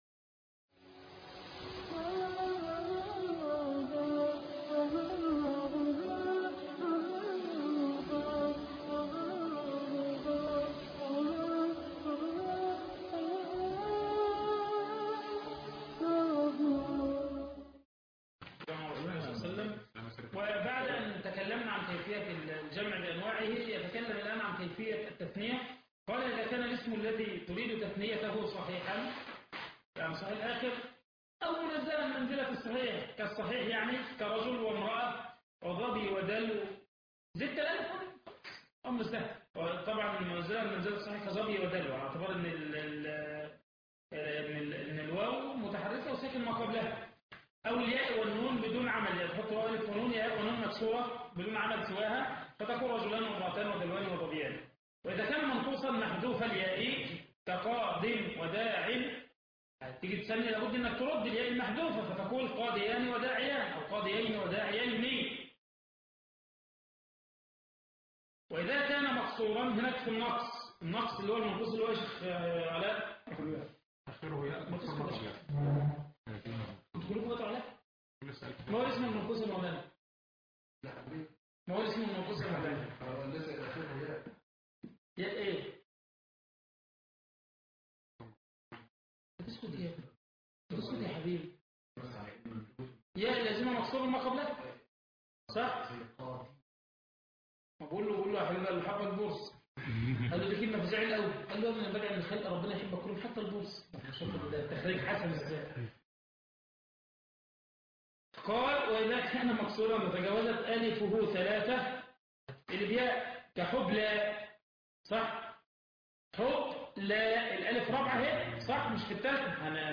شرح كتاب {شذا العرف في فن الصرف} المحاضرة الخامسة